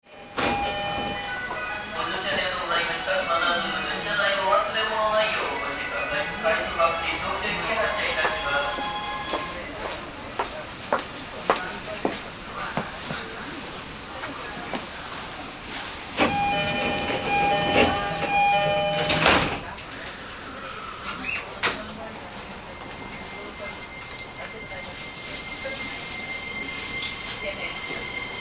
しかしながら不思議なモーター音と挟まれたくないドアは健在です。
走行音
TK02 E231系 真鶴→根府川 5:30 9/10 上の続きです。